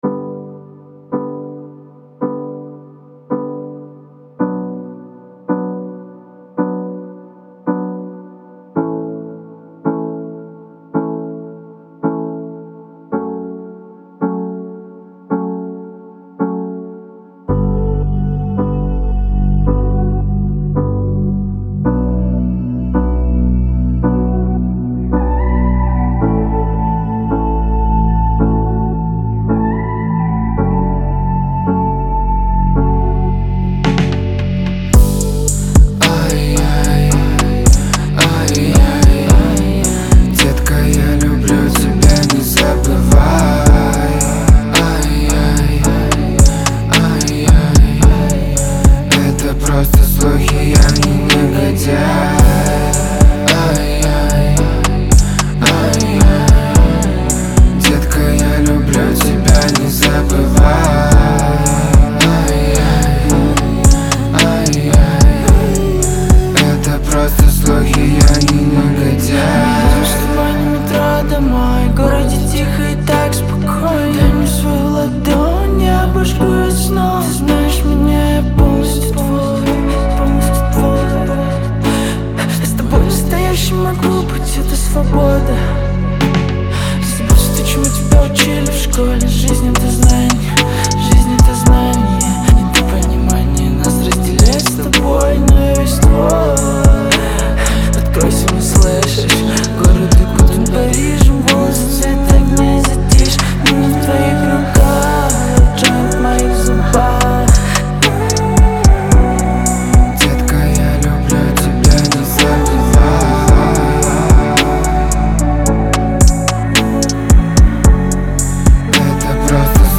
это яркая и запоминающаяся композиция в жанре хип-хоп